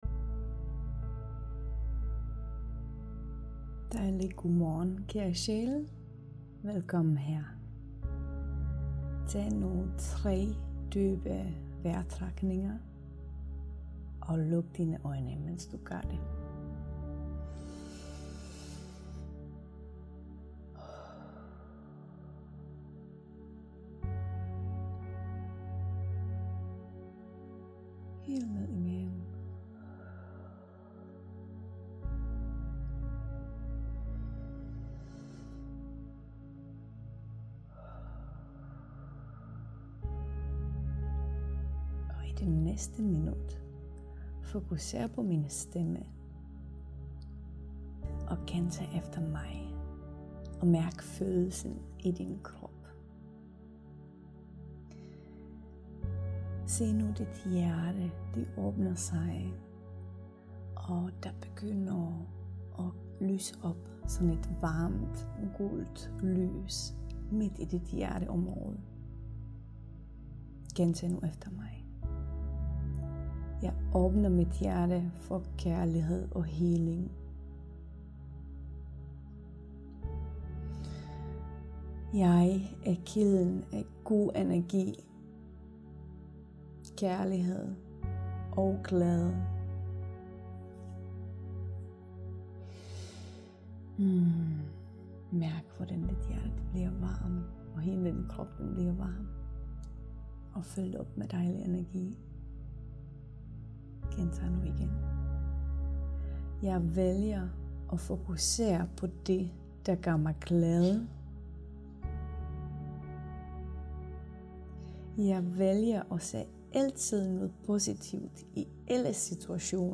Gør den samme energi tjek efter meditationen.